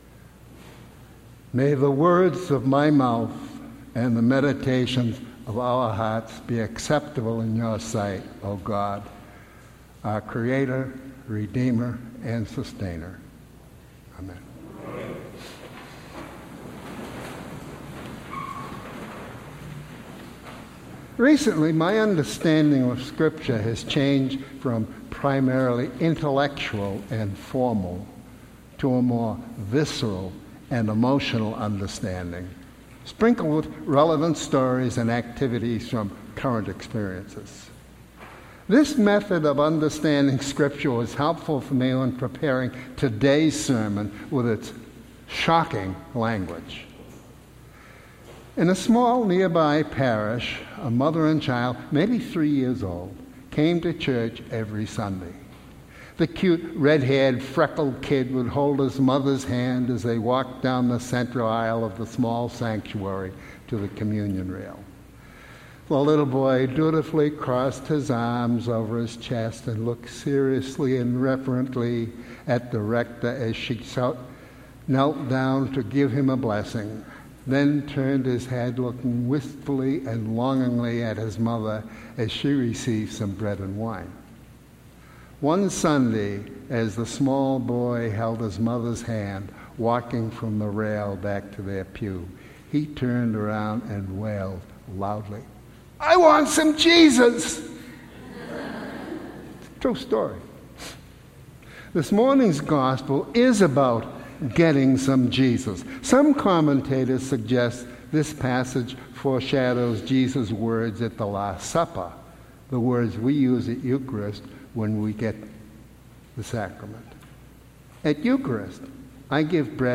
Sermon – August 19, 2018